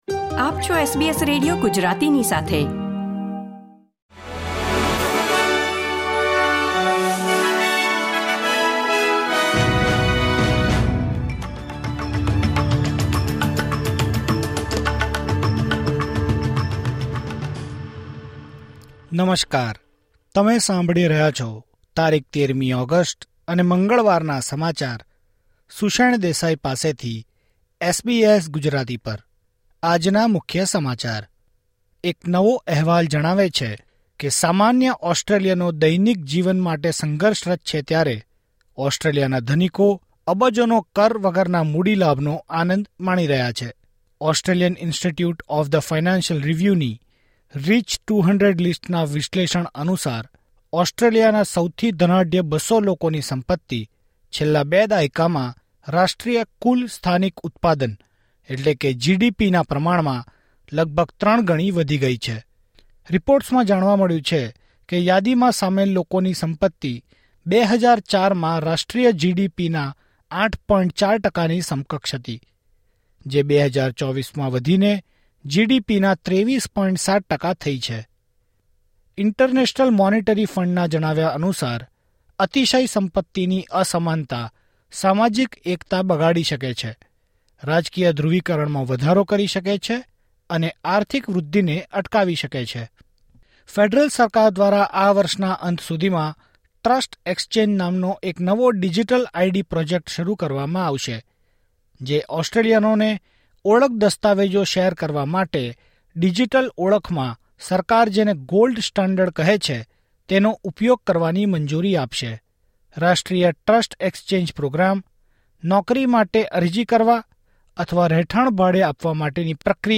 SBS Gujarati News Bulletin 13 August 2024